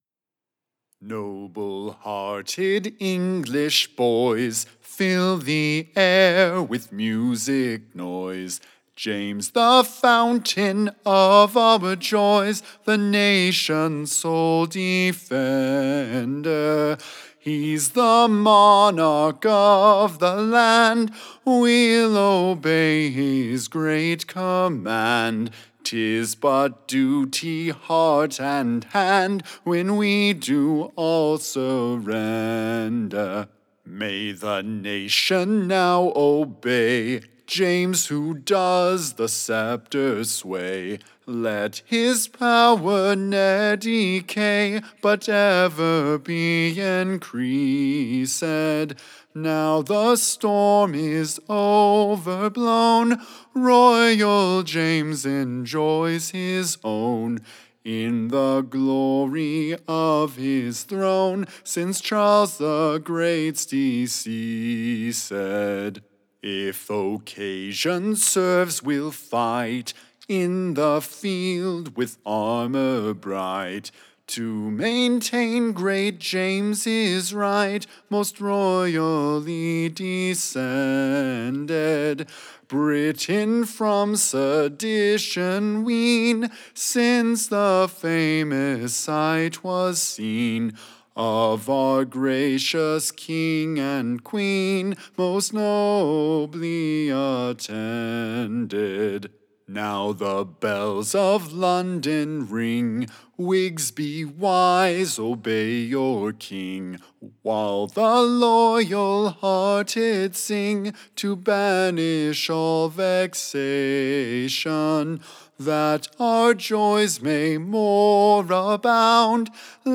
Ballad
Tune Imprint To the Tune of, The Cannons Rore.